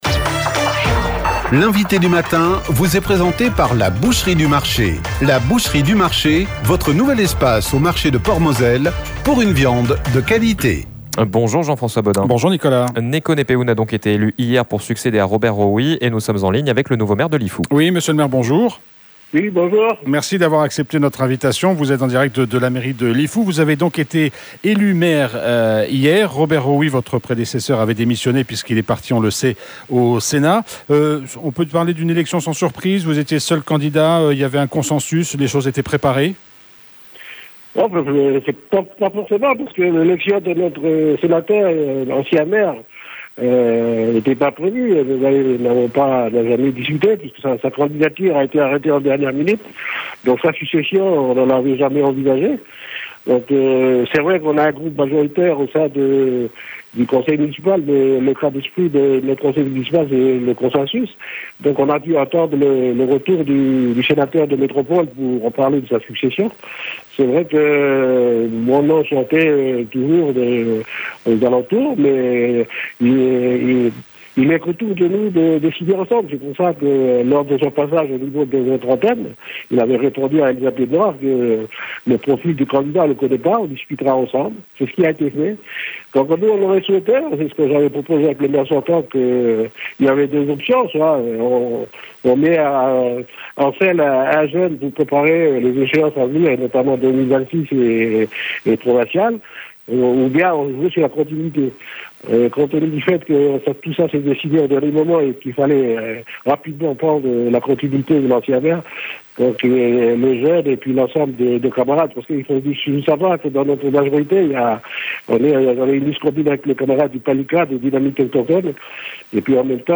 Menu La fréquence aux couleurs de la France En direct Accueil Podcasts L'INVITE DU MATIN : NEKO HNEPEUNE L'INVITE DU MATIN : NEKO HNEPEUNE 24 octobre 2023 à 09:53 Écouter Télécharger Néko Hnepeune, le nouveau maire de Lifou était avec nous ce matin par téléphone.